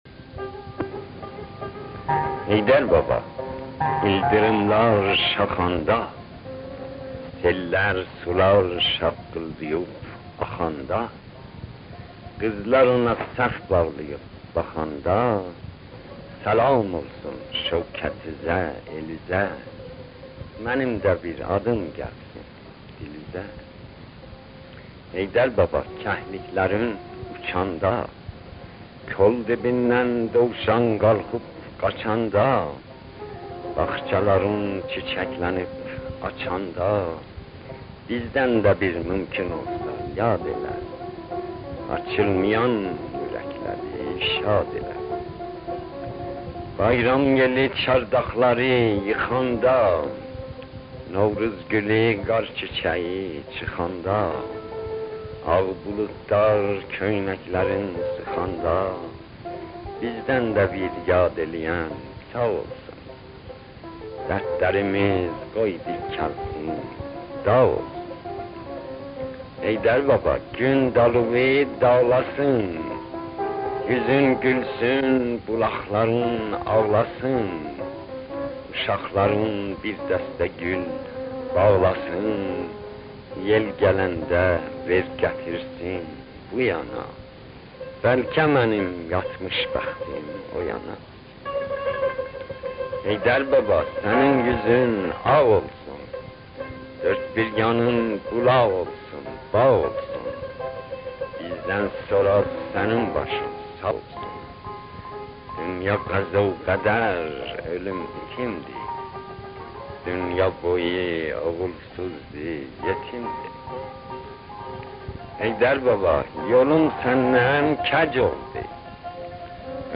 دکلمه از زبان خوده شهریار